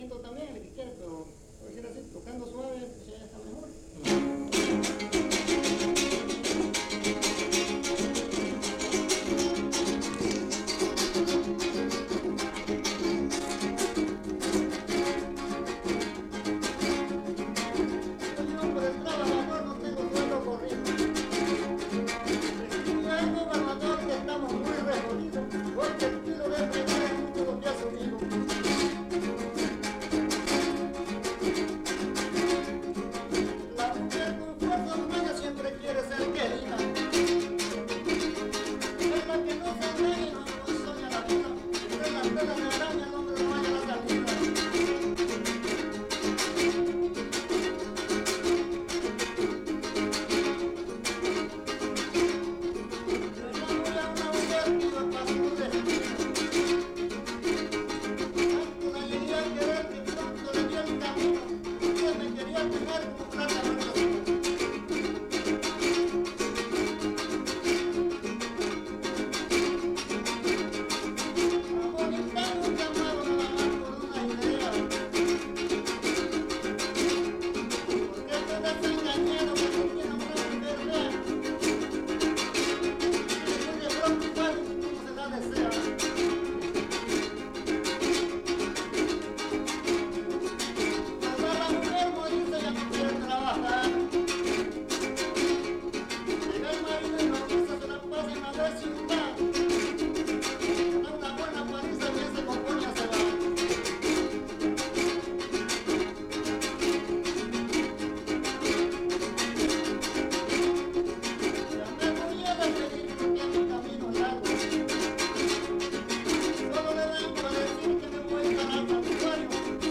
Fiesta de La Candelaria: investigación previa